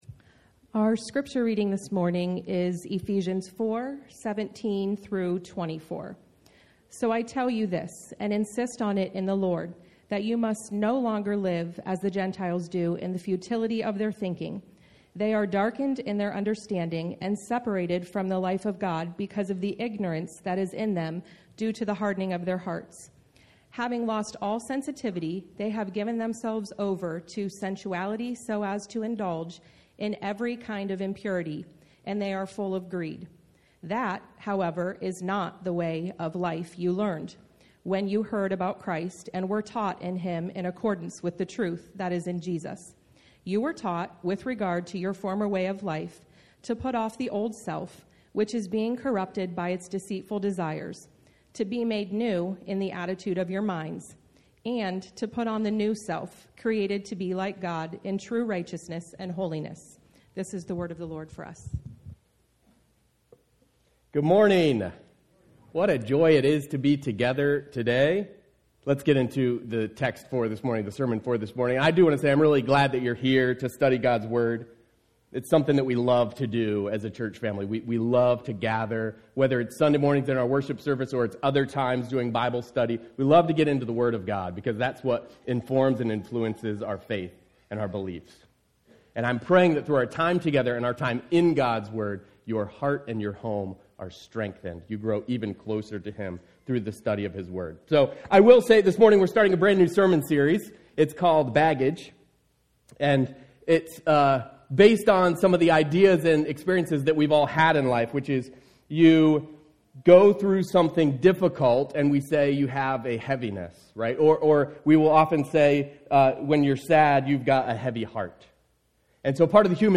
Sermons | Mennonite Christian Assembly